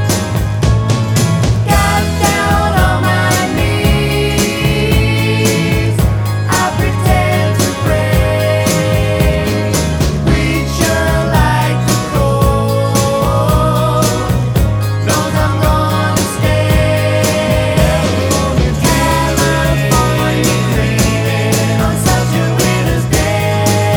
One Semitone Down Pop (1960s) 2:39 Buy £1.50